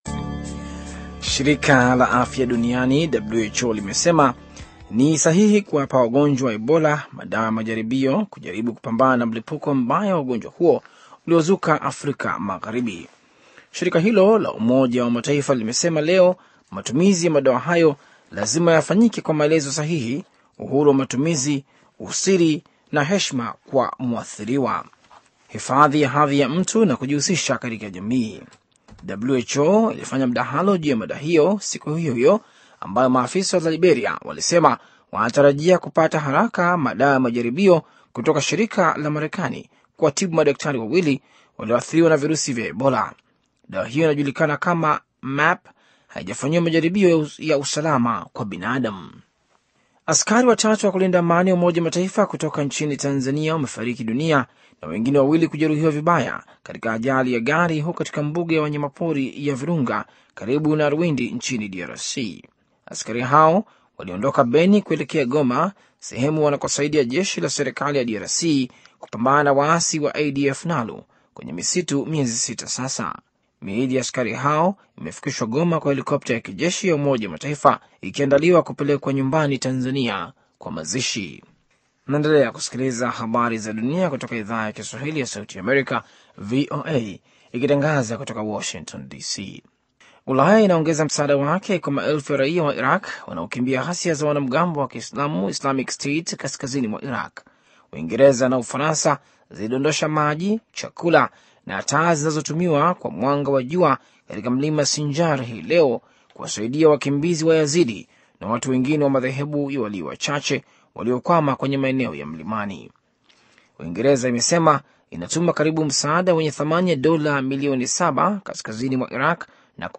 Taarifa ya habari - 6:57